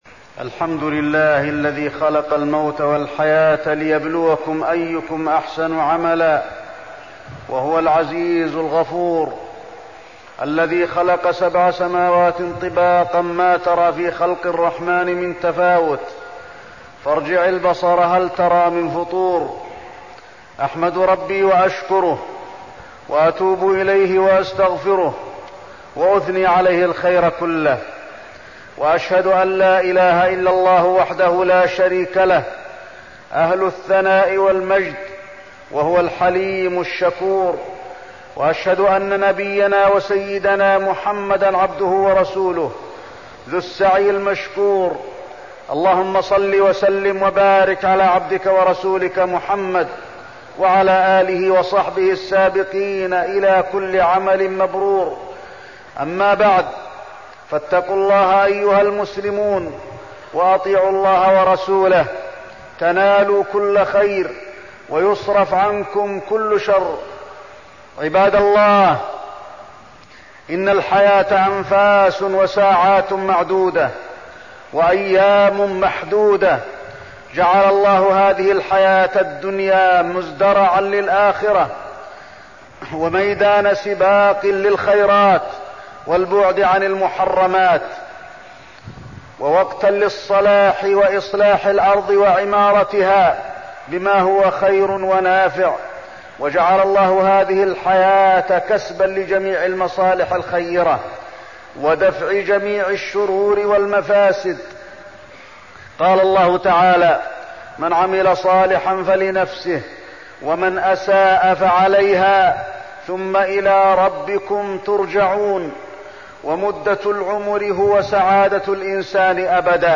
تاريخ النشر ٨ ربيع الأول ١٤١٦ هـ المكان: المسجد النبوي الشيخ: فضيلة الشيخ د. علي بن عبدالرحمن الحذيفي فضيلة الشيخ د. علي بن عبدالرحمن الحذيفي اغتنام الأوقات